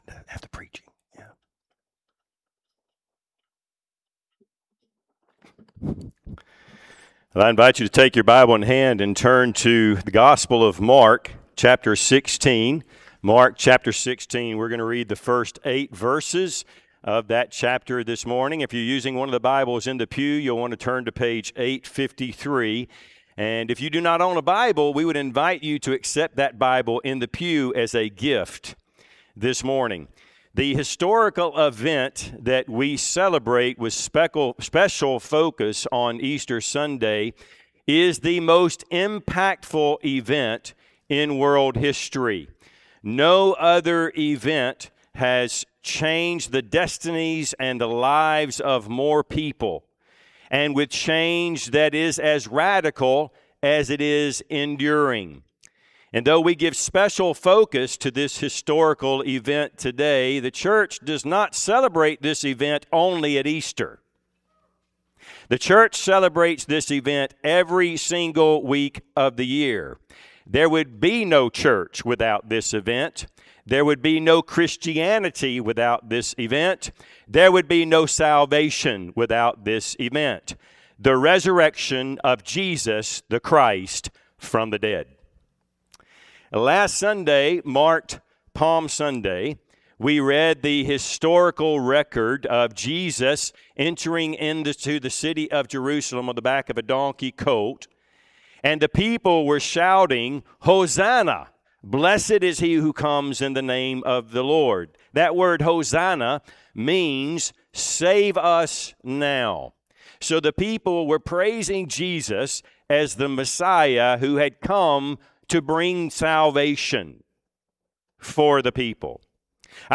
Service Type: Sunday AM Topics: believers' resurrection , Christ's resurrection , justification , new birth « God’s Overcoming Grace God’s Glorious Gospel